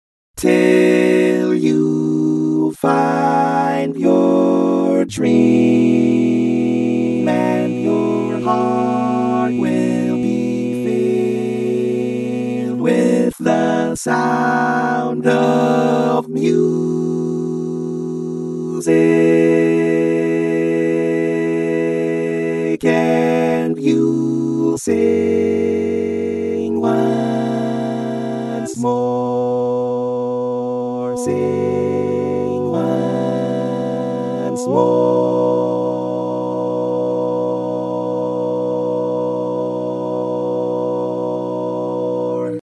Key written in: B Major
How many parts: 4
Type: Barbershop
All Parts mix:
Learning tracks sung by